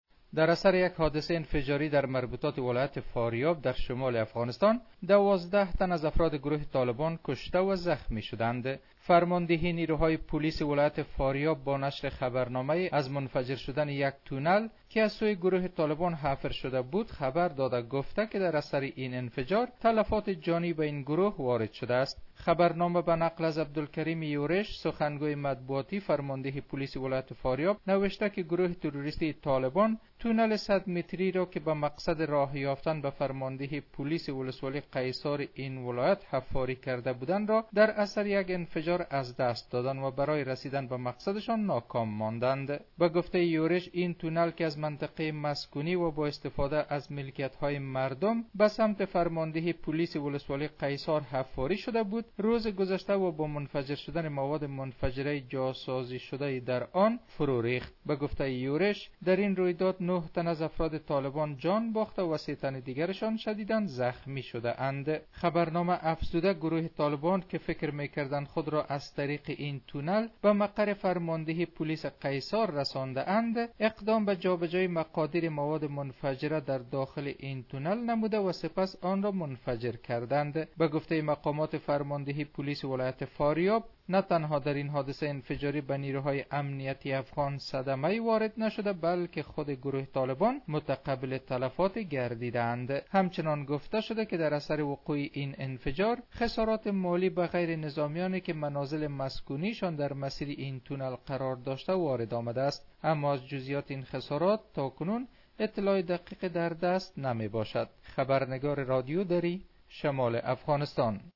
به گزارش رادیو دری ، پلیس ولایت فاریاب اعلام کرد: در انفجار روز جمعه تونل حفر شده طالبان در ولسوالی قیصار این ولایت، ۹ عضو این گروه کشته و ۳ تن دیگر زخمی شدند.